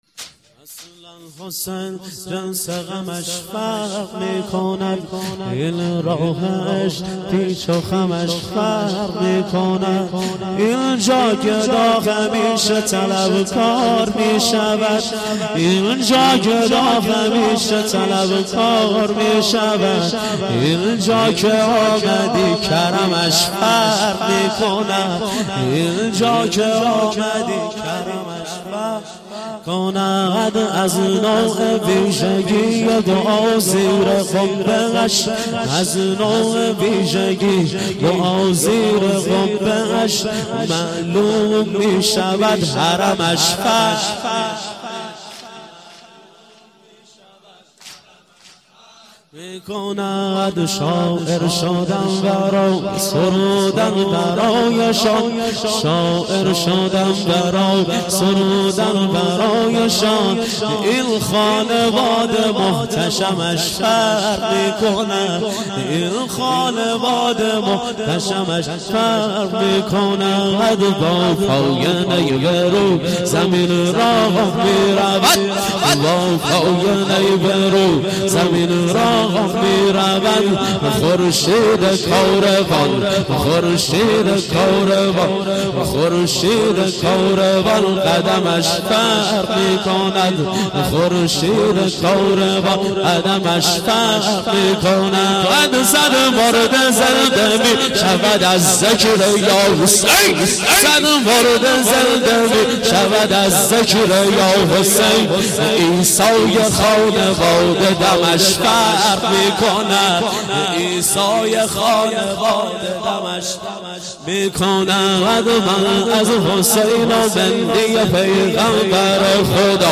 واحد - اصلا حسین جنس غمش فرق می کند